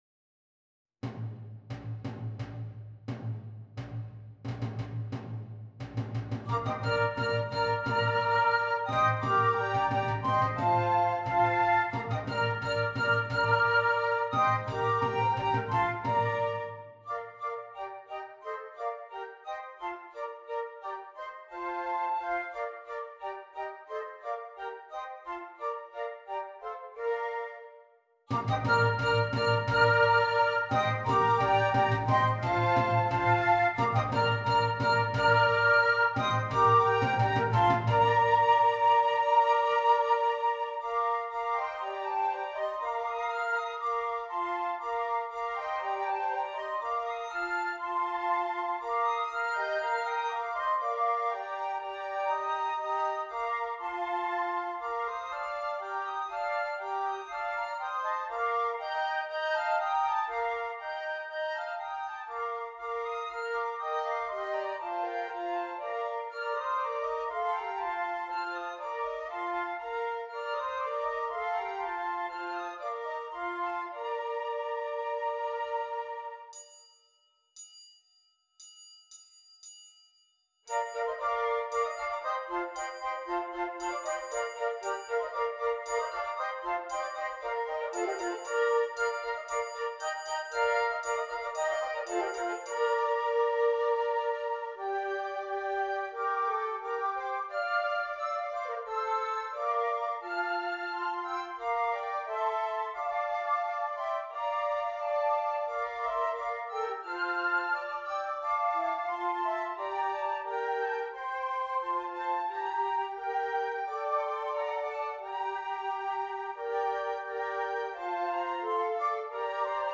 6 Flutes
Brilliant fortes are contrasted with smooth lyrical playing.